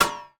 etfx_impact_metal03.wav